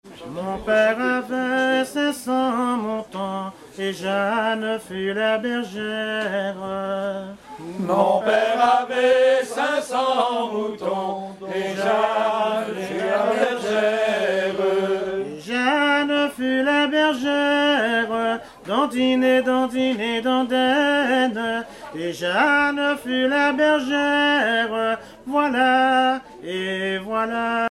chansons anciennes recueillies en Guadeloupe
Pièce musicale inédite